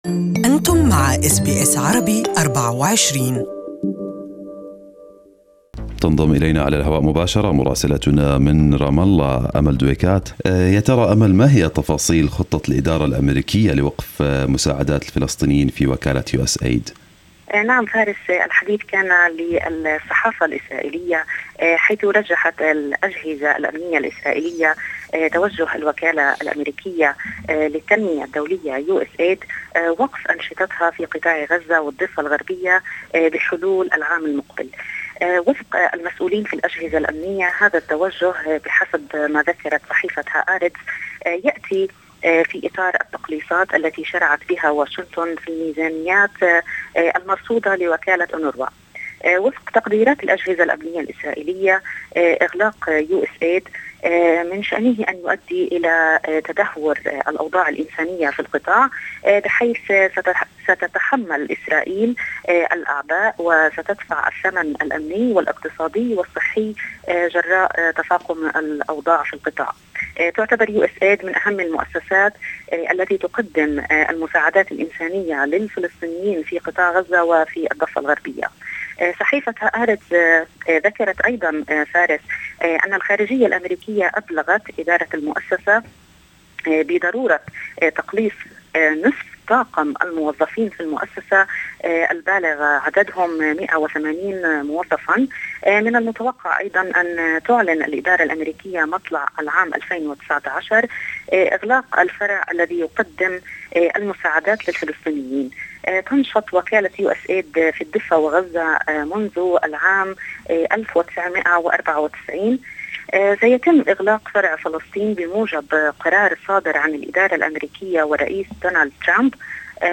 Our correspondent from Ramallah has the details